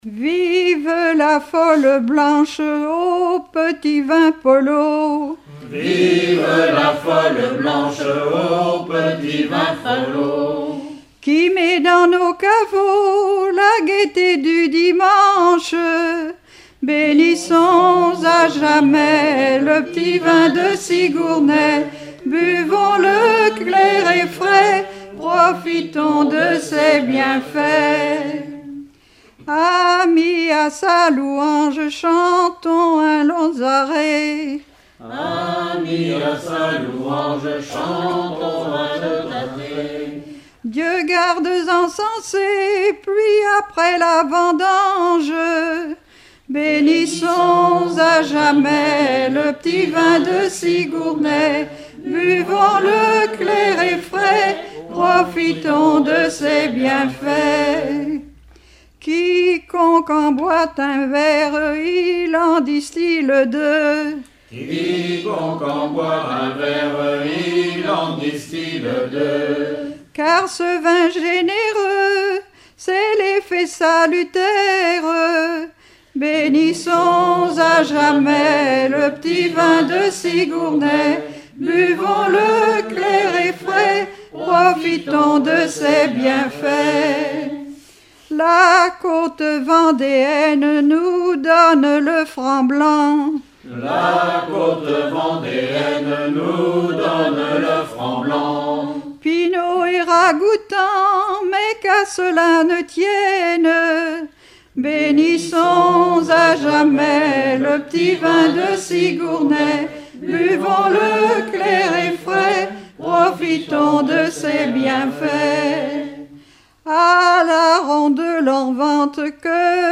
circonstance : bachique
enregistrement d'un collectif lors d'un regroupement cantonal
Pièce musicale inédite